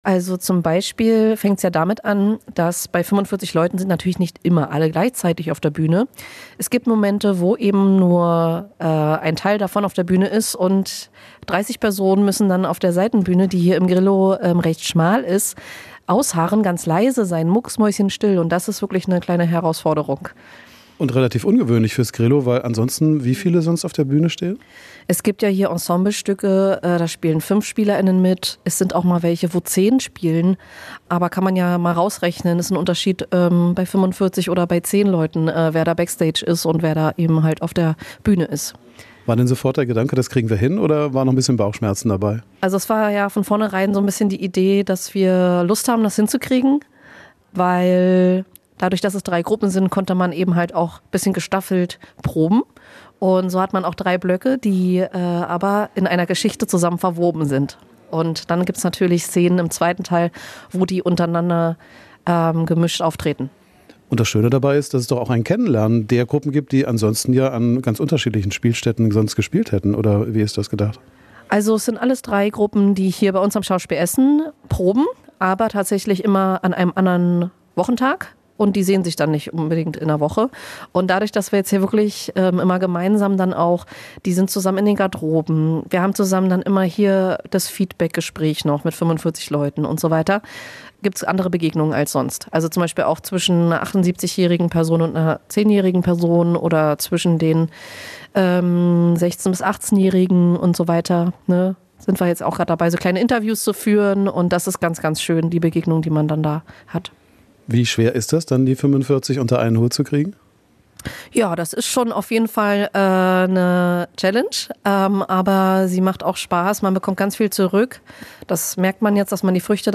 Hintergrund ist der Zusammenschluss von drei Theater Clubs des Stadt Ensembles. Jetzt müssen 45 Schauspielerinnen und Schauerspieler unter einen Hut gebracht werden. Wir waren bei den Proben dabei.